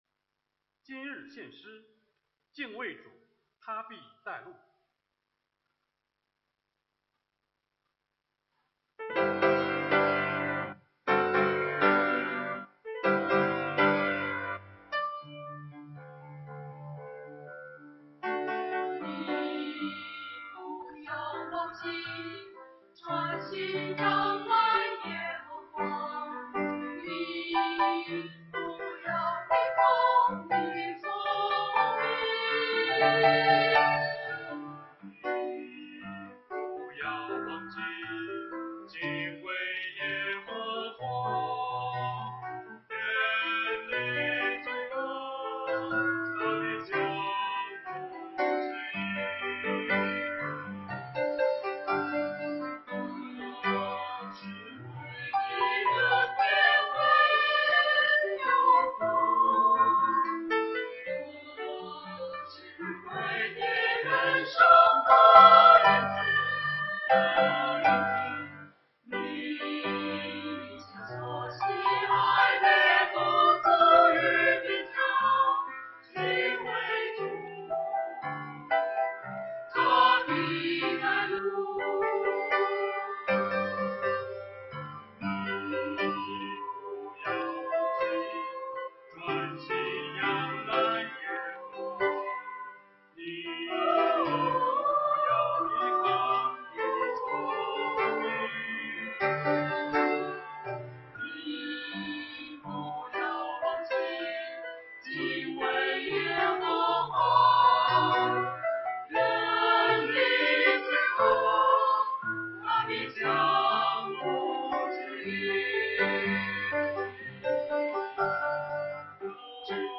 团契名称: 青年诗班 新闻分类: 诗班献诗 音频: 下载证道音频 (如果无法下载请右键点击链接选择"另存为") 视频: 下载此视频 (如果无法下载请右键点击链接选择"另存为")